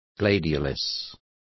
Also find out how estoque is pronounced correctly.